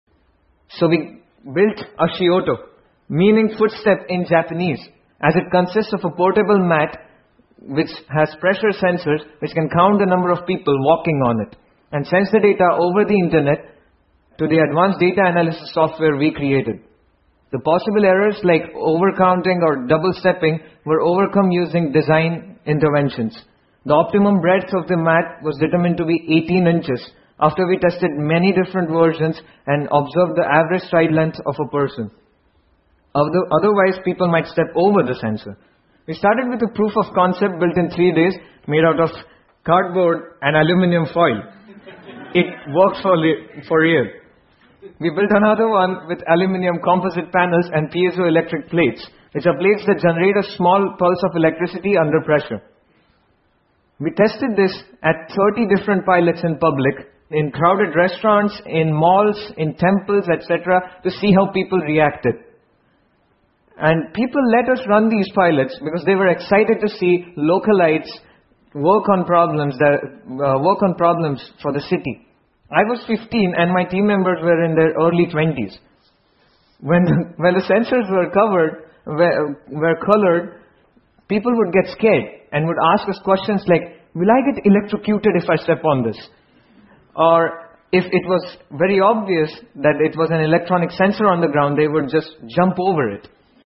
TED演讲:一个能防止人群踩踏事件的救生发明() 听力文件下载—在线英语听力室